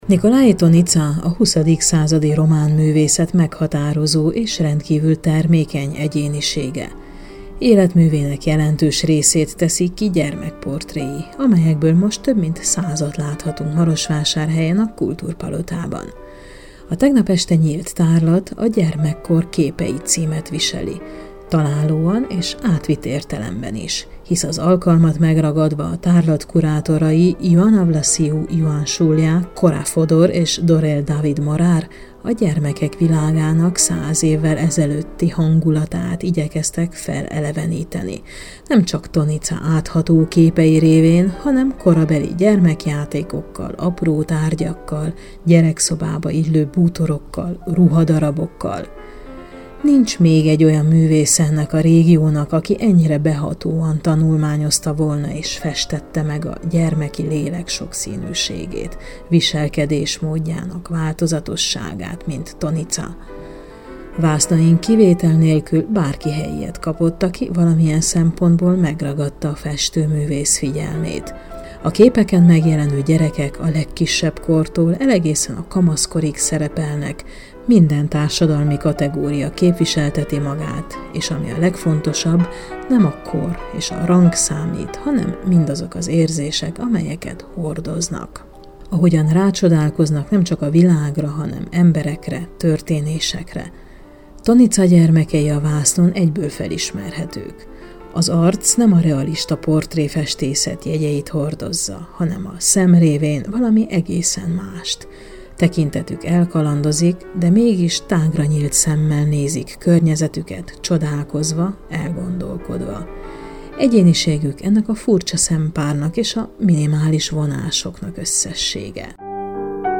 A következő összeállításban Soós Zoltánnal, a Maros Megyei Múzeum igazgatójával beszélgetünk.